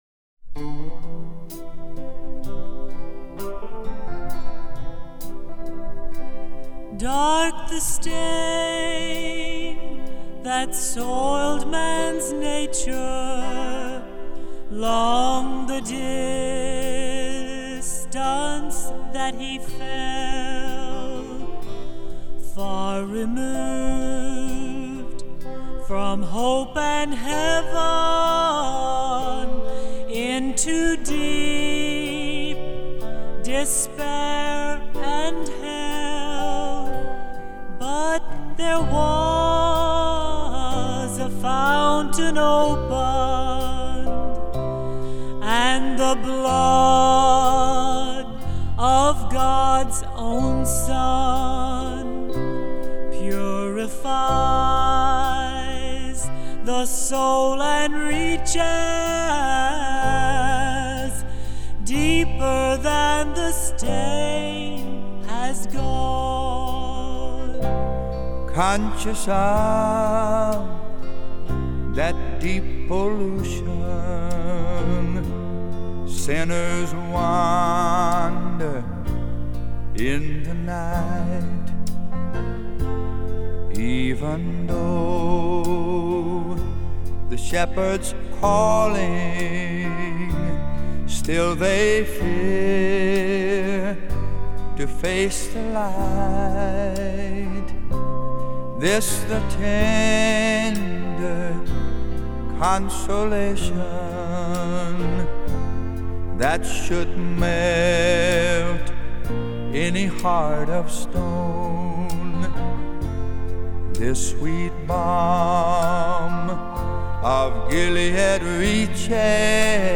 Click Here to read the lyrics to this beautiful hymn